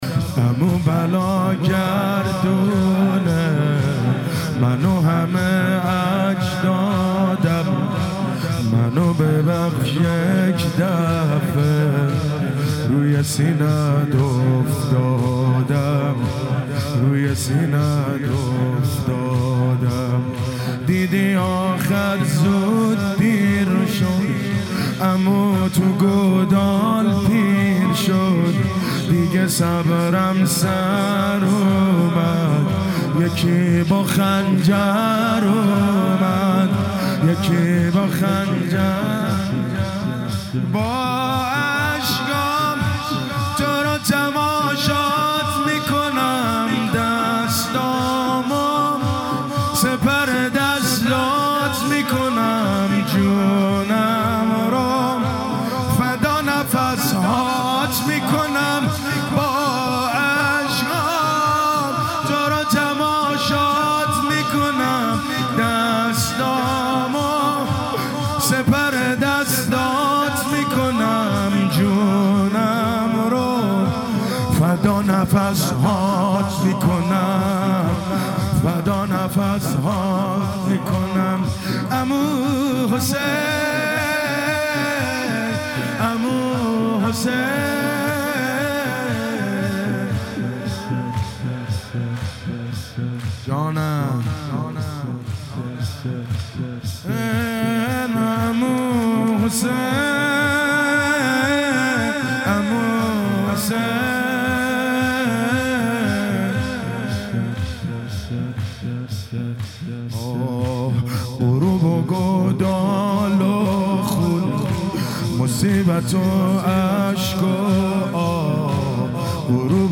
شب پنج محرم 1395
سینه زنی زمینه ، شور